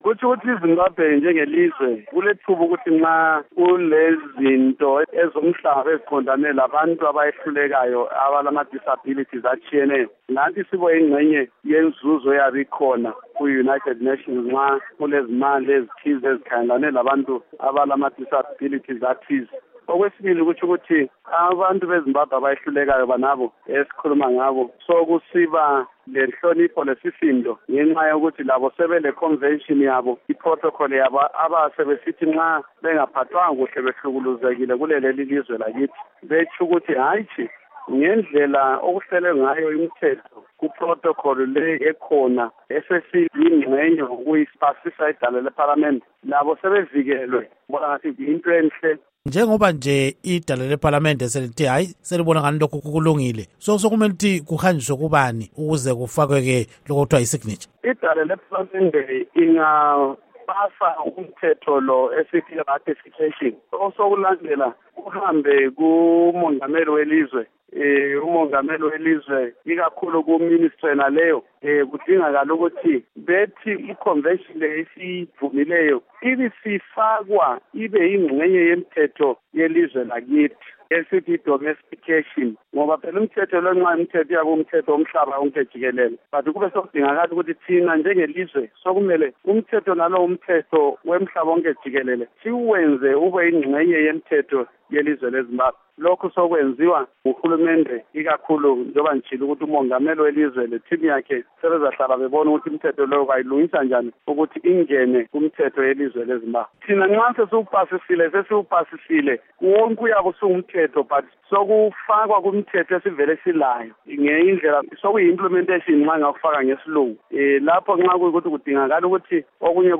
Ingxoxo Esiyenze LoMnu. Lovemore Moyo